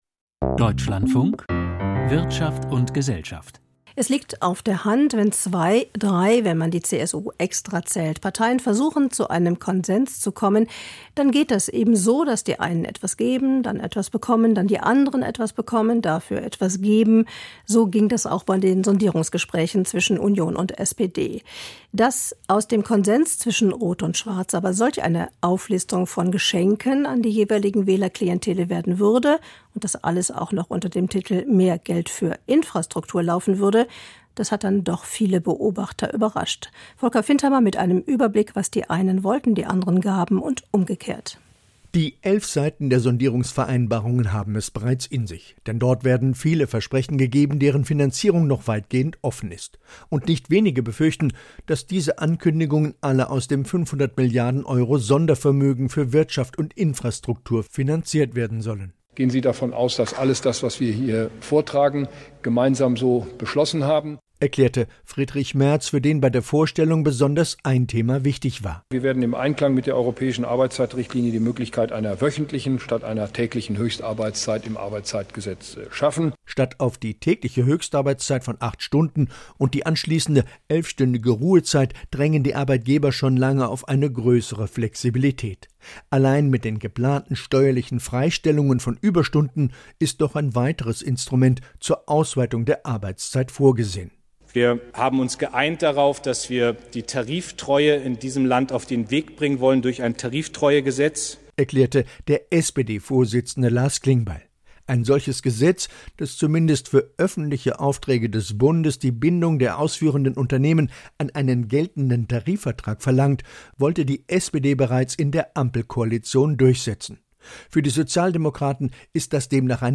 Kommentar - Sondierung ohne Kompass für die Wirtschaft